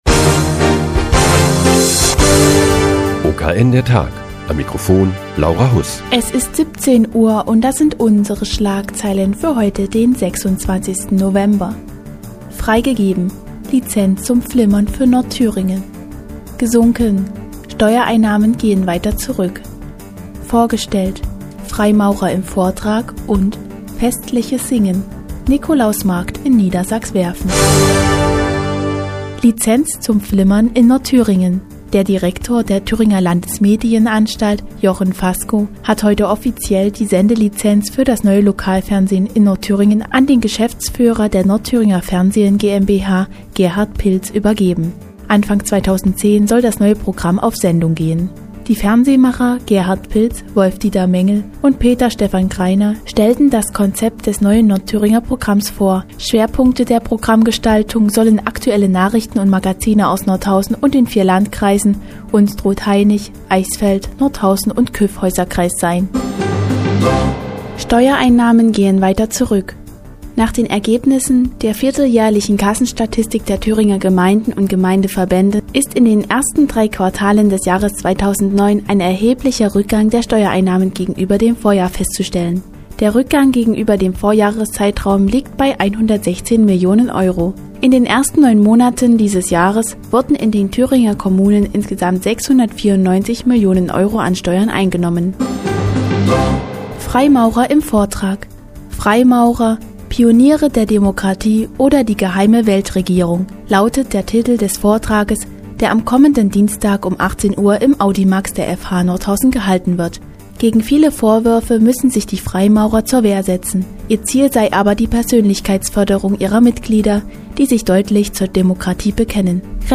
Die tägliche Nachrichtensendung des OKN ist nun auch in der nnz zu hören. Heute geht es um neues Lokalfernsehen in Nordthüringen, gesinkene Steuereinnahmen und den Adventsmarkt in Niedersachswerfen.